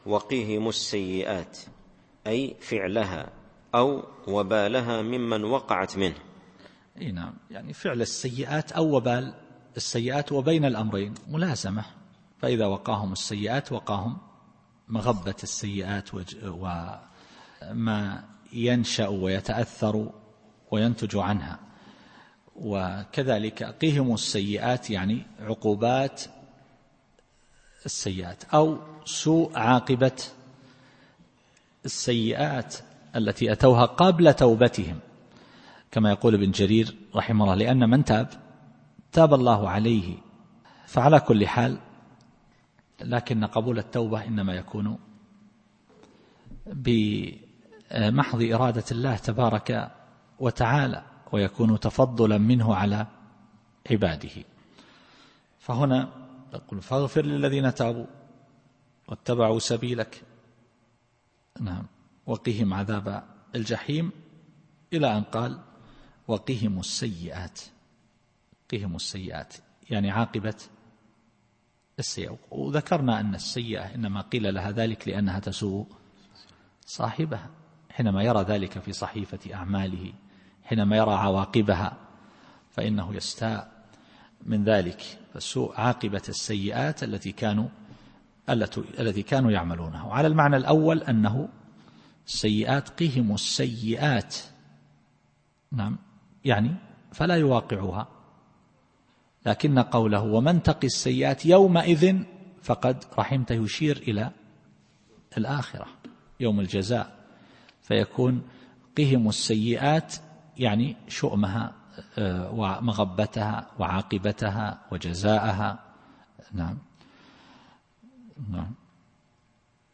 التفسير الصوتي [غافر / 9]